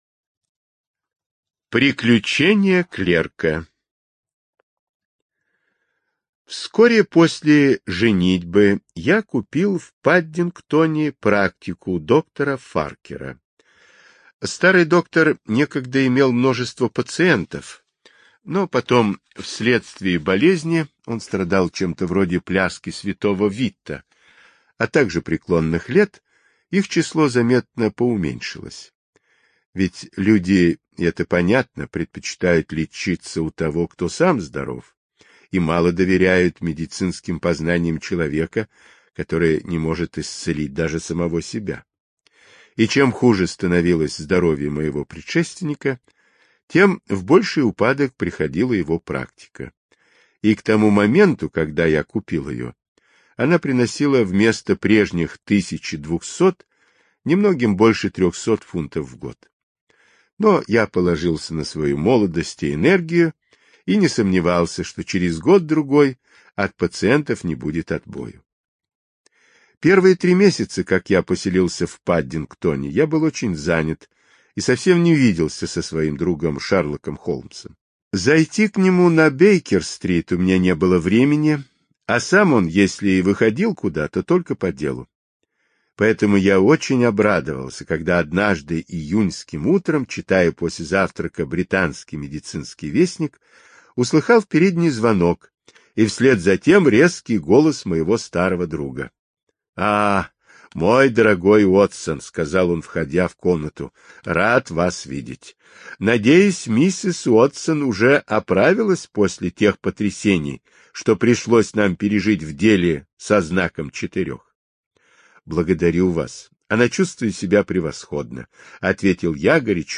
Приключение клерка — слушать аудиосказку Артур Конан Дойл бесплатно онлайн